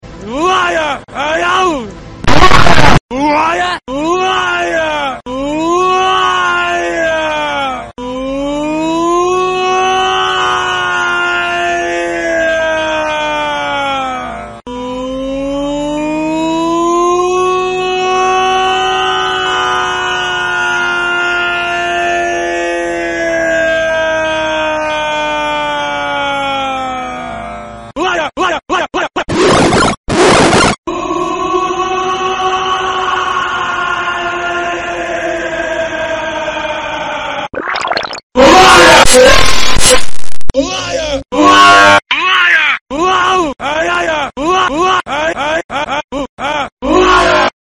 Anakin screaming LIAR Sound sound effects free download
Anakin screaming LIAR - Sound Variations